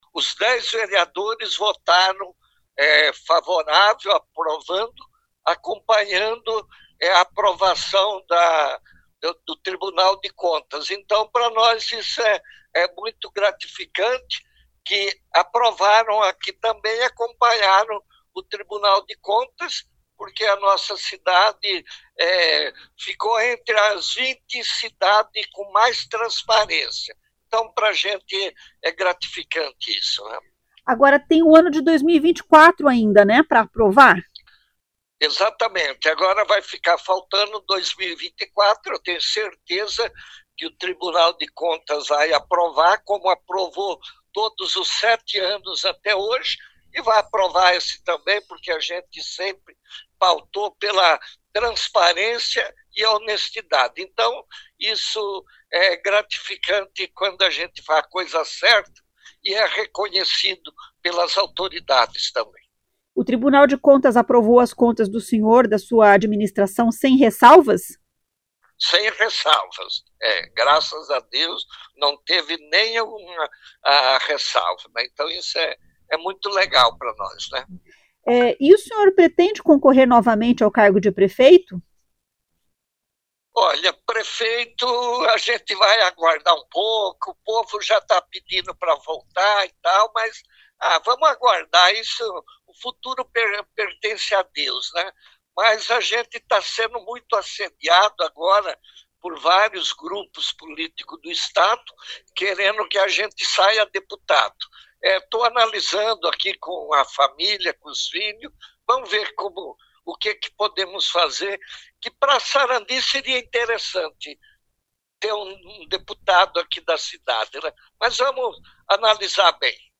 O ex-prefeito Walter Volpato diz que o parecer foi aprovado por unanimidade pelos vereadores; seguindo o Tribunal de Contas do Estado que julgou as contas regulares sem ressalvas. Ouça o que diz o ex-prefeito: